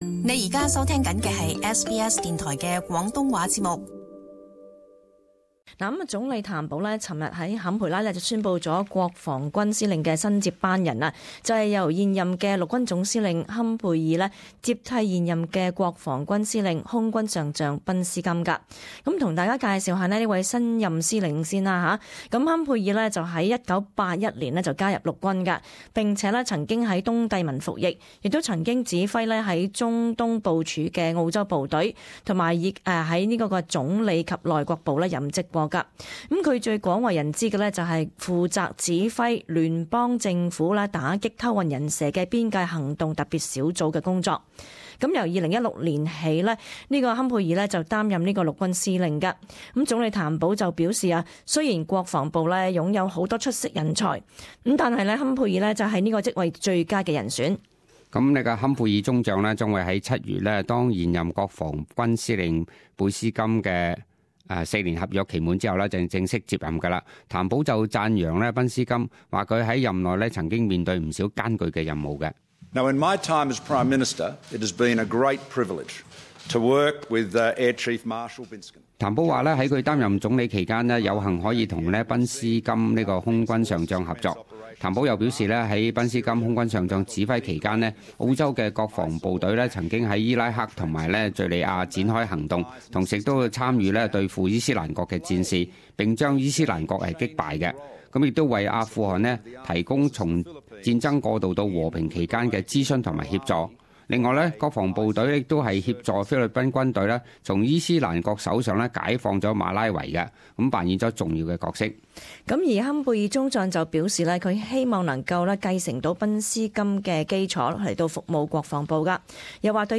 【时事报导】陆军司令坎贝尔 获委任为澳洲国防军司令